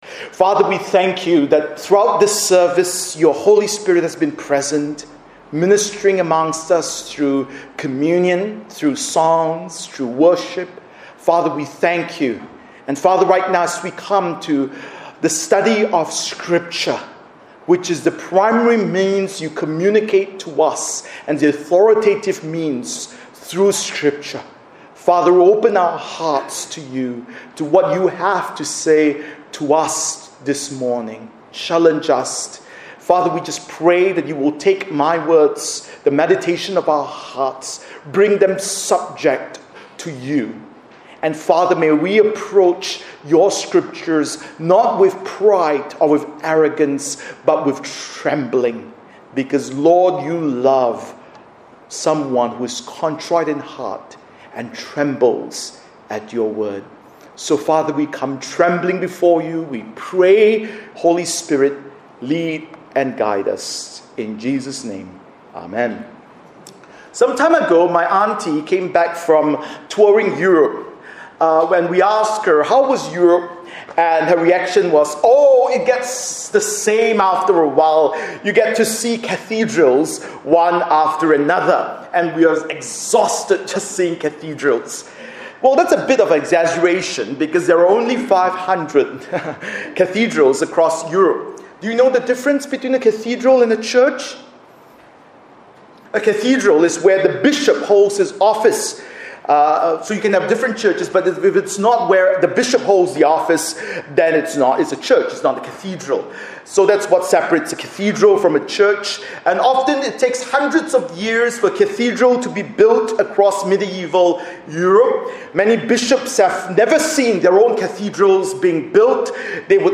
Bible Text: Matthew 5:1-12 | Preacher